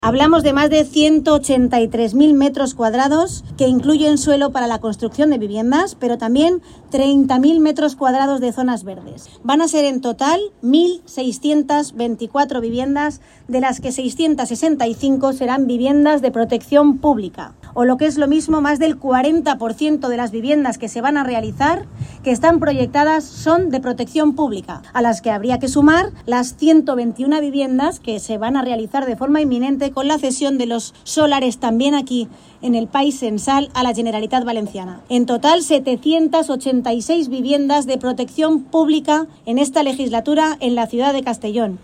Corte de voz de la alcaldesa de Castellón, Begoña Carrasco